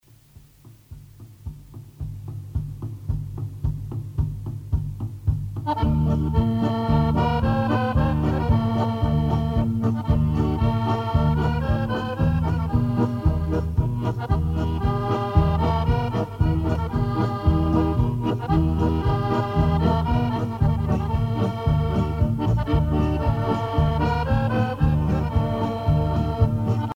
danse : paso musette